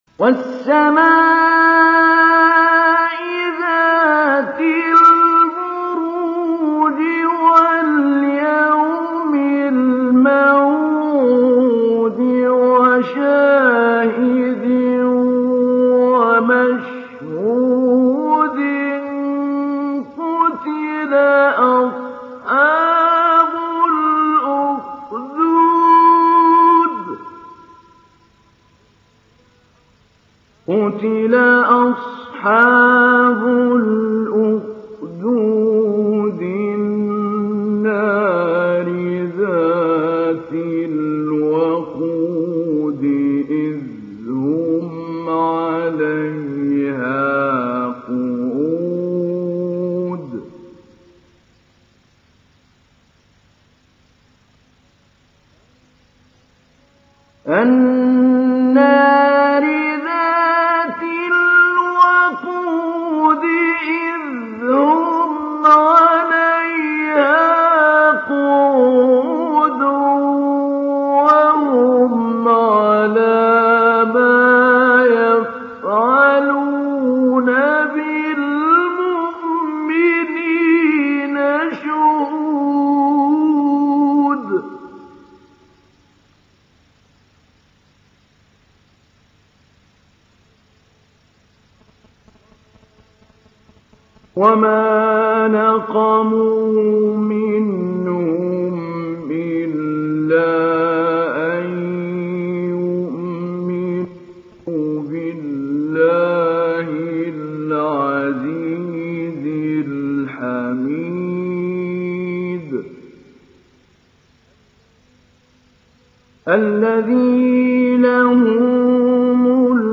Download Surat Al Buruj Mahmoud Ali Albanna Mujawwad